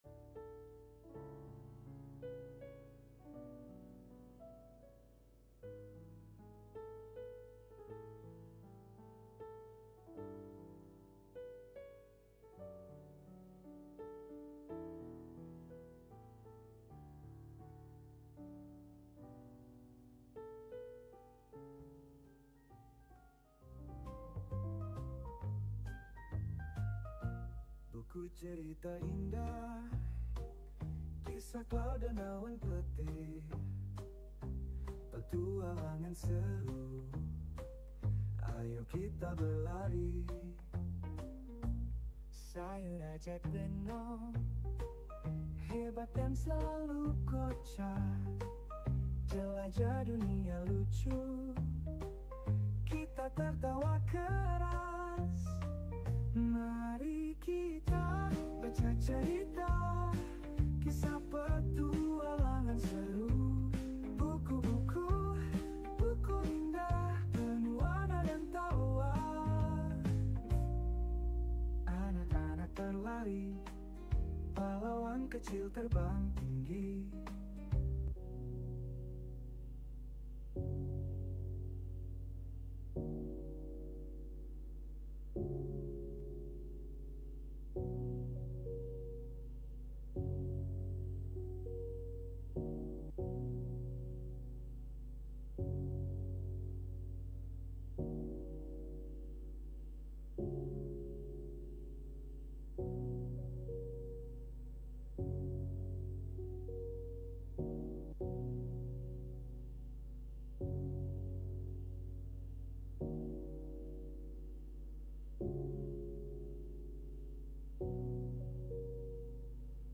Yang Kecil Pun Berarti – Ibadah Minggu (Pagi 1)